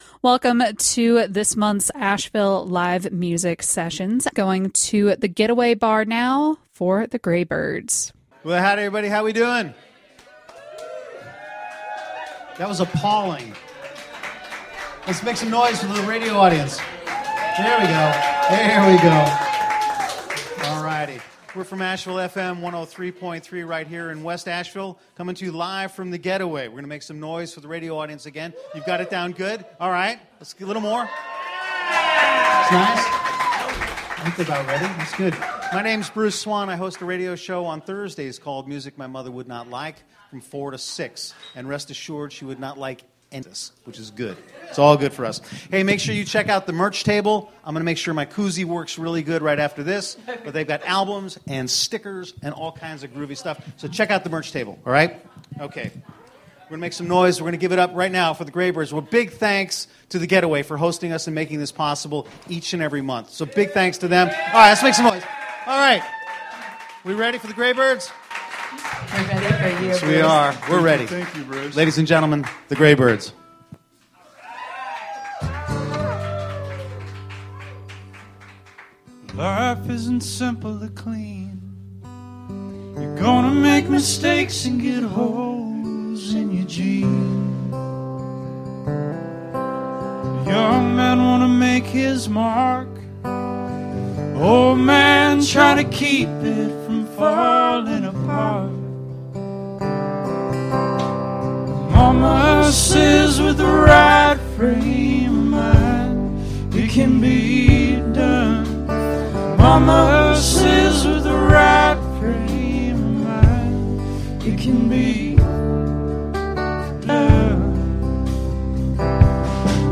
Live from The Getaway River Bar
Recorded during safe harbor – Explicit language warning